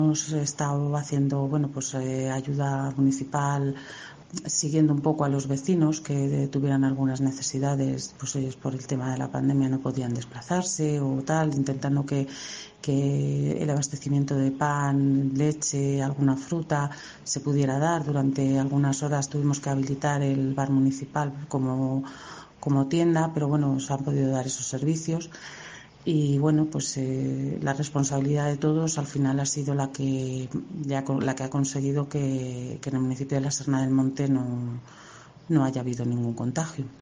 En COPE hemos hablado con Mónica Gutiérrez, concejal y teniente de alcalde de La Serna Del Monte: “Nuestro municipio se ha mantenido limpio de virus, gracias a que desde el minuto uno se tomaron las medidas necesarias.